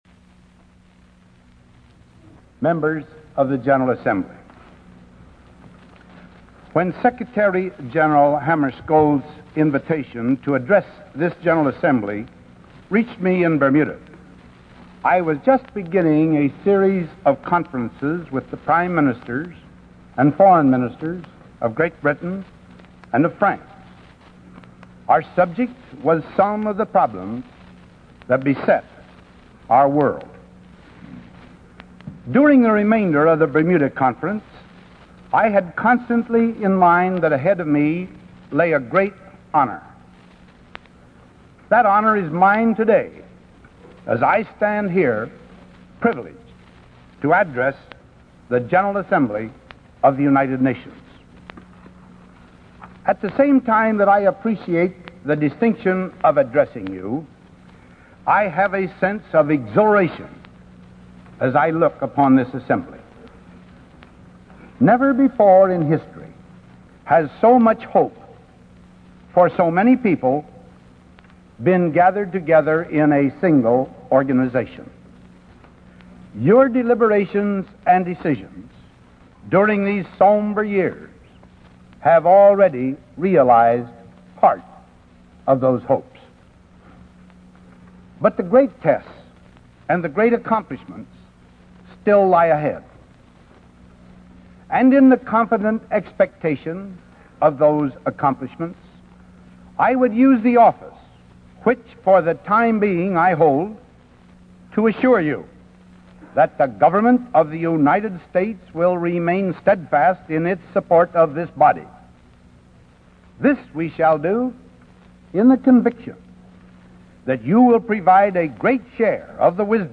Delivered 8 December 1953, United Nations General Assembly